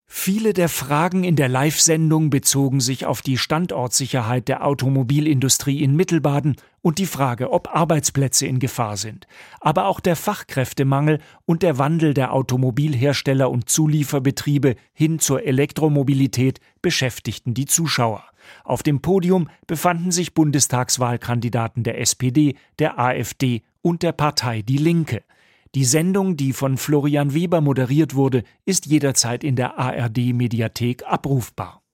SWR-Sendung aus dem Unimog-Museum vor der Bundestagswahl
"Zur Sache" live aus Gaggenau: Wege aus der Automobilkrise
Unter diesem Motto stand am Donnerstag die SWR-Sendung "Zur Sache" live aus dem Unimog-Museum Gaggenau.